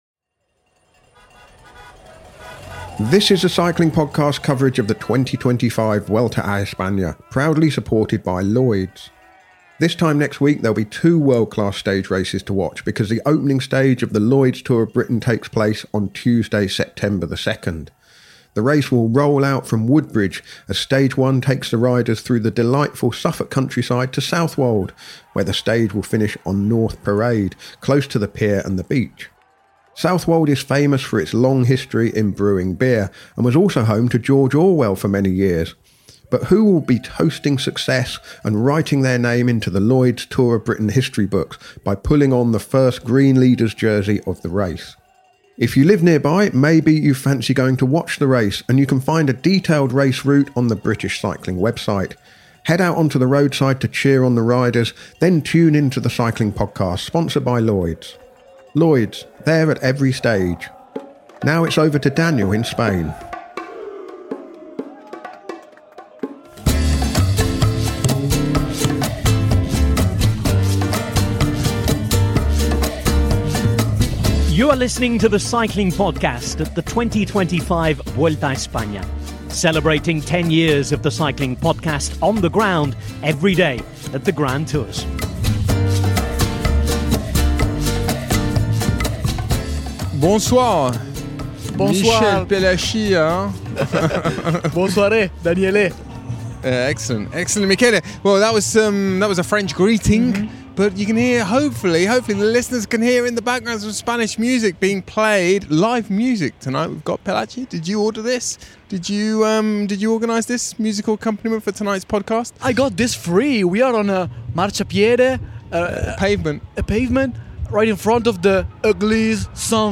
Join us for daily coverage of the Vuelta a España recorded on the road as the race makes its way from Turin to Madrid. Our daily coverage features race analysis, interviews and daily postcards from Spain.